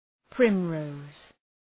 Προφορά
{‘prım,rəʋz}